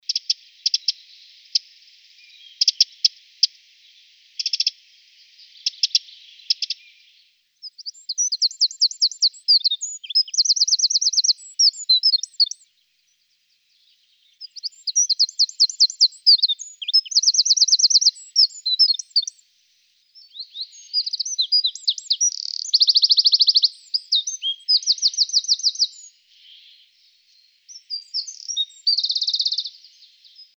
Orto botanico - Scricciolo
Aree di nidificazione (verde chiaro) Popolazioni stanziali (verde scuro) Aree di svernamento (blu) Il suo canto, definito da trilli prolungati e musicali, risuona acuto fra i cespugli. Per essere così piccino, certamente ha una voce potentissima.
scricciolo.mp3